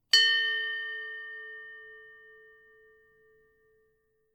Ship Bell Single Ring
bell ding dong nautical naval ships-bell sound effect free sound royalty free Sound Effects